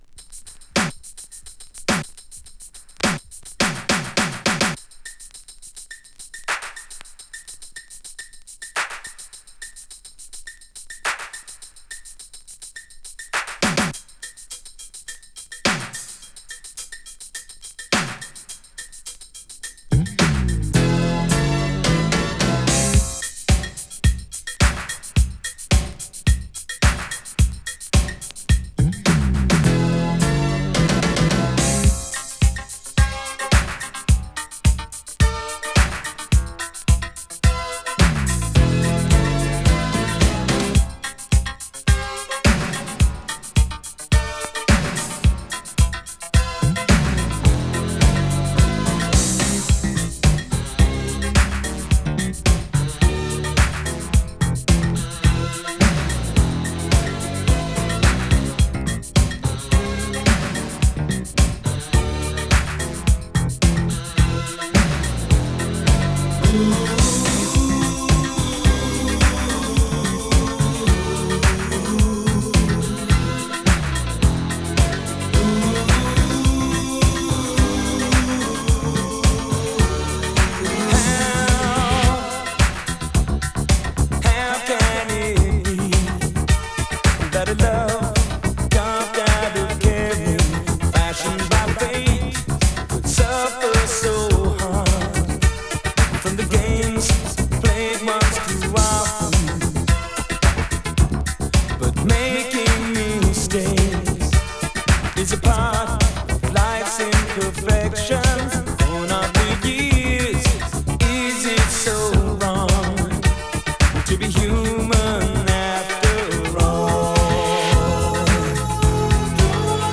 Garage Classic
(Remix)